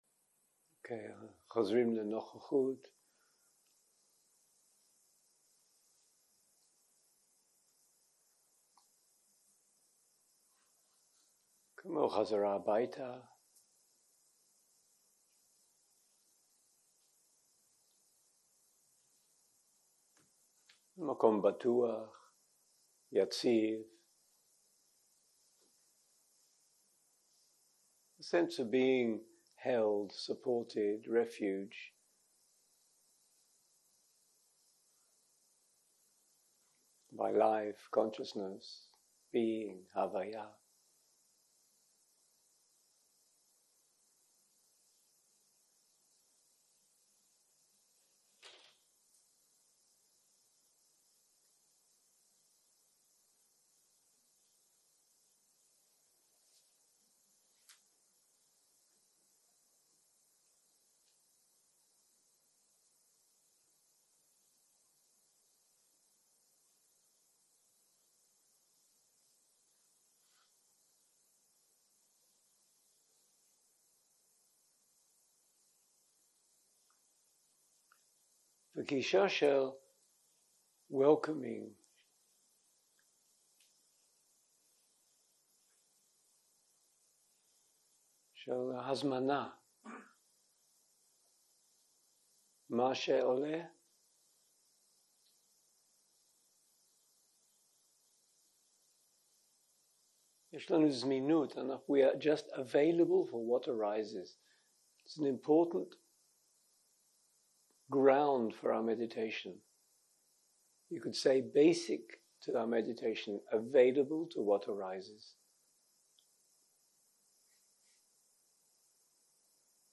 הקלטה 18 - יום 7 - צהרים - מדיטציה מונחית - מטא
Dharma type: Guided meditation שפת ההקלטה